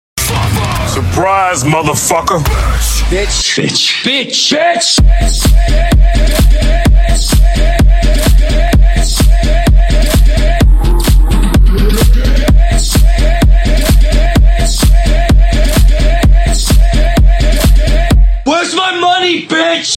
• Качество: 160, Stereo
ритмичные
громкие
Metalcore
remix
Bass
mash up
house